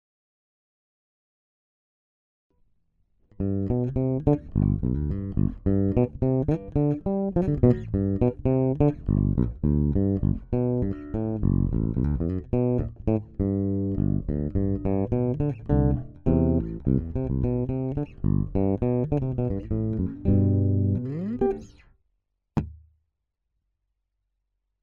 tak, spravil som malu ukazkovu nahravku s Infinity
snimace 50/50 - HB+J
bass trosku pridane (len vylezene z aretacnej drazky)
vysty trosku ubrate
struny asi 3mesacne Warwick red
Wow, krásnej pevnej zvuk...